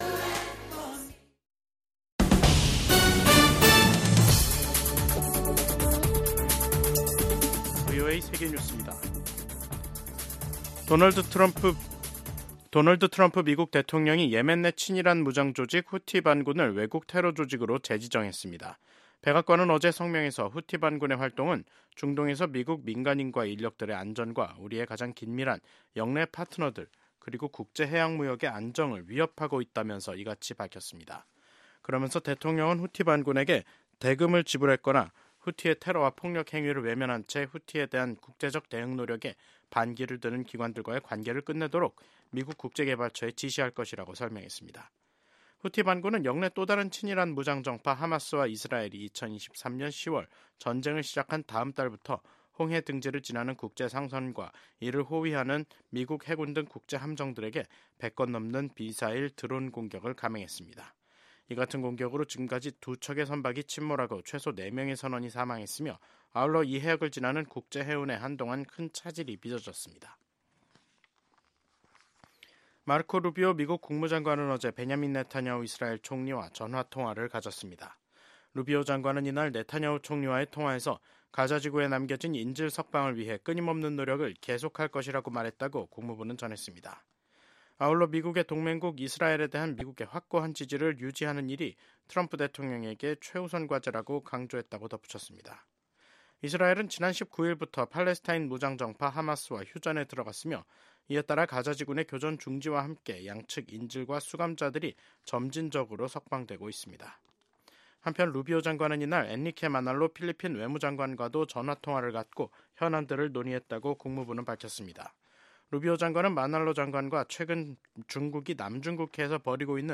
VOA 한국어 간판 뉴스 프로그램 '뉴스 투데이', 2025년 1월 23일 3부 방송입니다. 미국 공화당 의원들은 도널드 트럼프 대통령의 두 번째 임기를 환영하며, 북한과 중국, 러시아, 이란 등 독재국가들에 대한 강경 대응을 예고했습니다. 마르코 루비오 미국 국무장관은 조태열 한국 외교부 장관은 도널드 트럼프 대통령 취임 사흘날 첫 전화 통화를 하고 북핵 문제에 대해 긴밀한 공조를 유지하기로 했습니다.